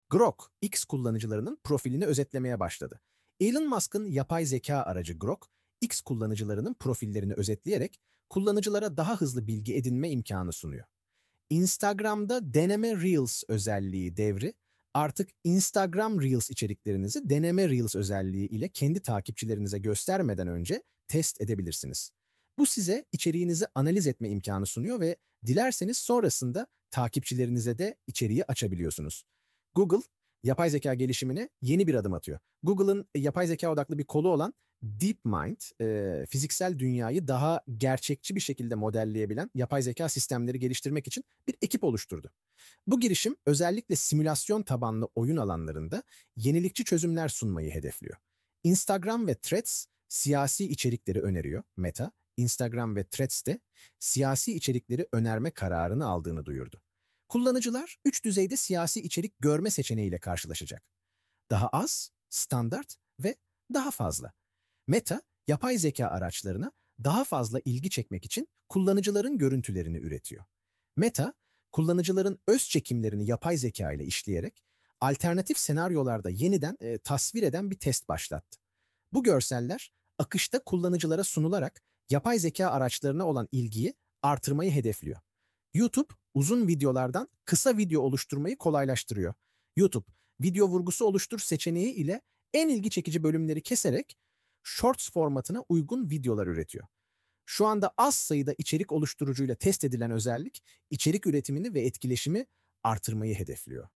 Sosyal-Medya-Gelismeler-Ocak-2025-Sesli-Anlatim-Turkce.mp3